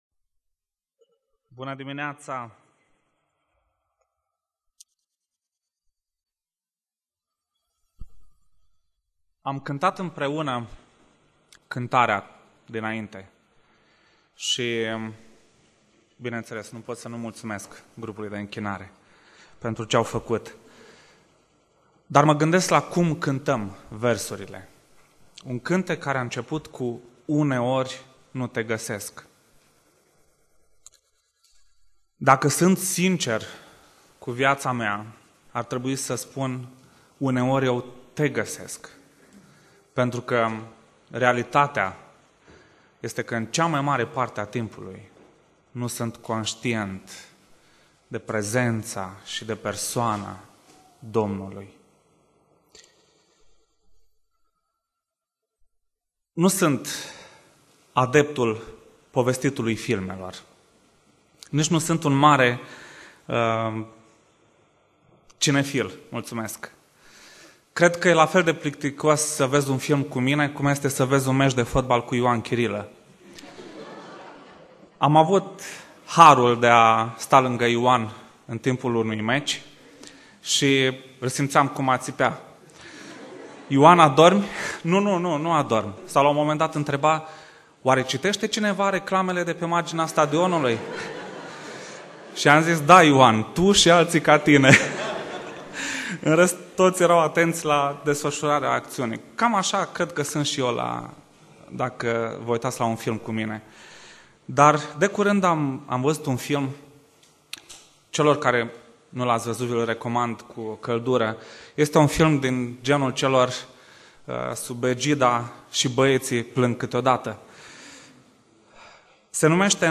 Predica Exegeza - Matei 25